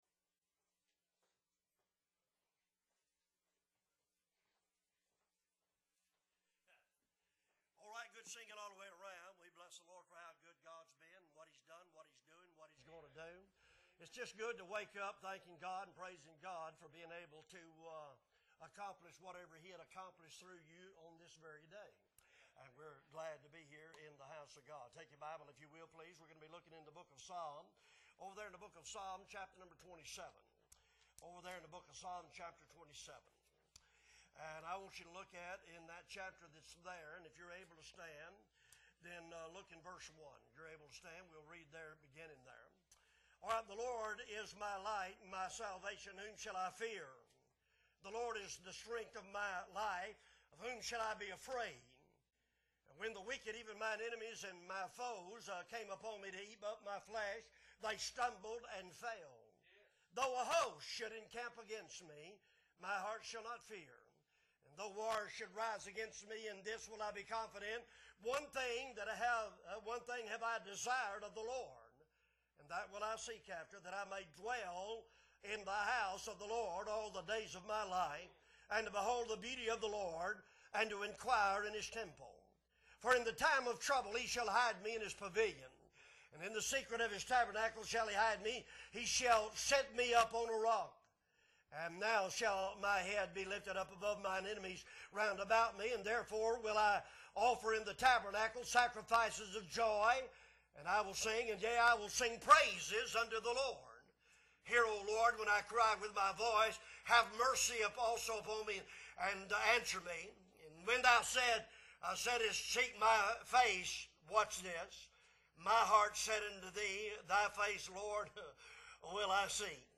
September 24, 2023 Morning Service - Appleby Baptist Church